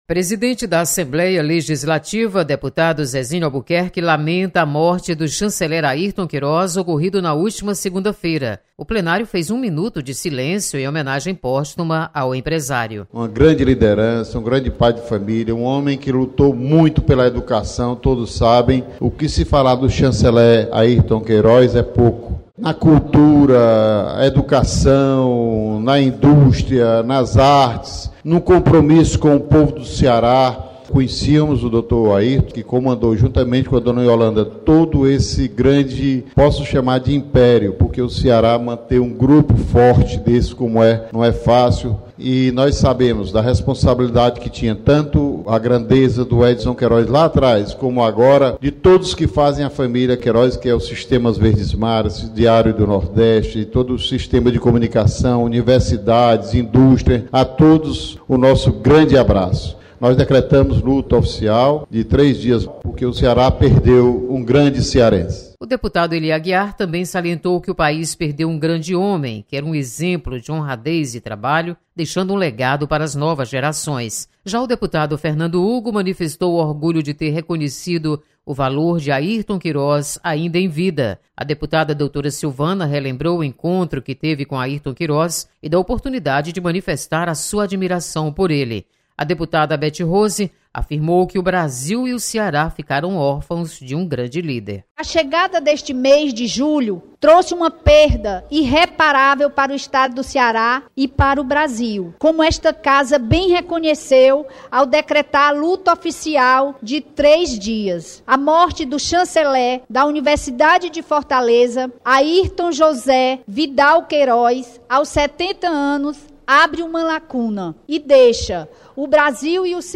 Deputados fazem um minuto de silêncio em homenagem póstuma ao empresário Airton Queiroz. Repórter